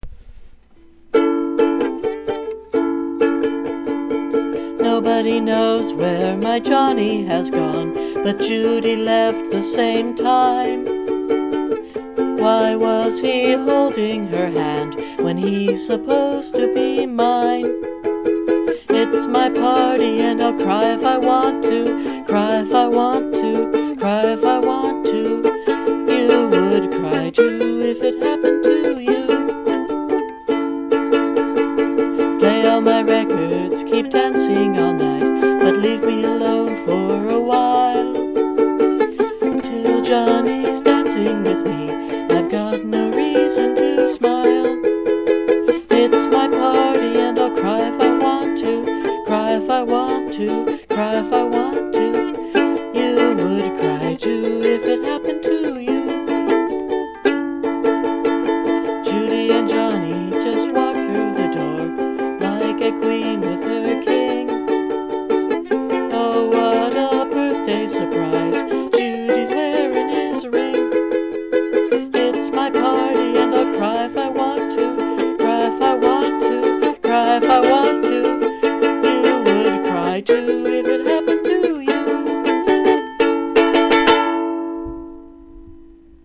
ukulele crushing power
Your singing improves with each chorus, an excellent effort.